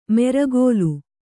♪ meregōlu